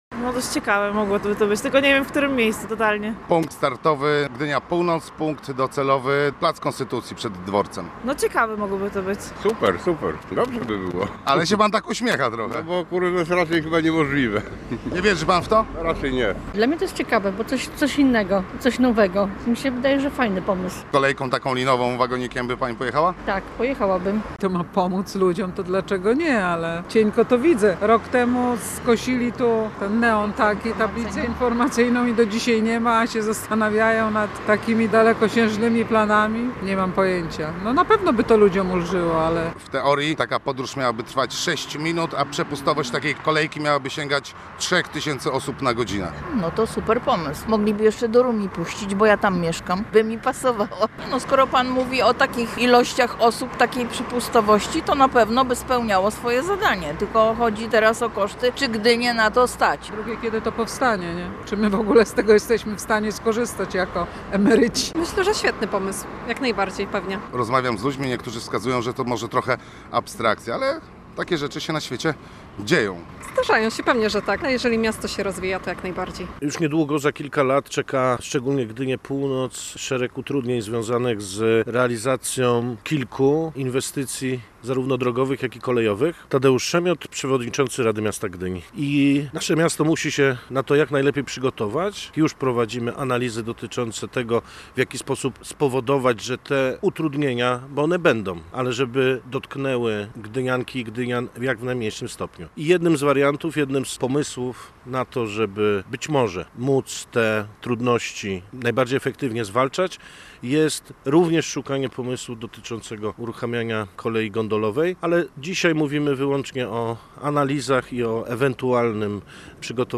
Co o pomyśle mówią mieszkańcy Gdyni? Posłuchaj materiału naszego reportera: https